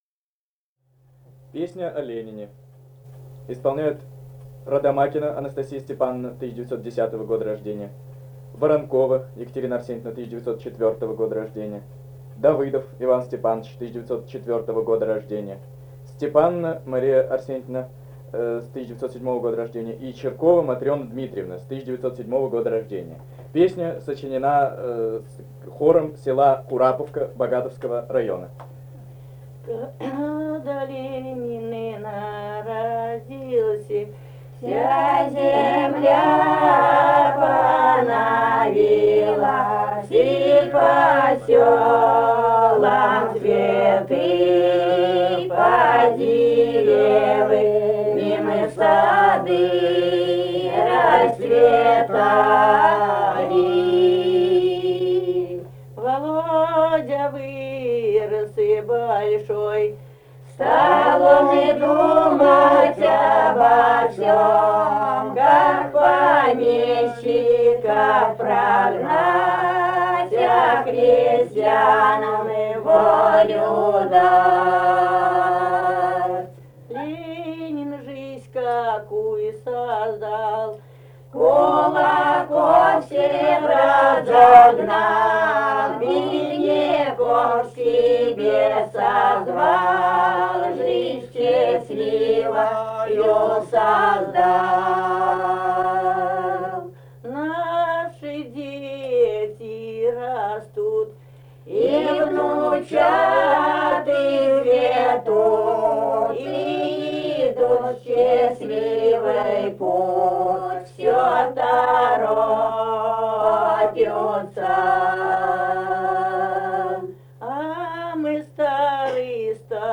Этномузыкологические исследования и полевые материалы
Самарская область, с. Кураповка Богатовского района, 1972 г. И1318-14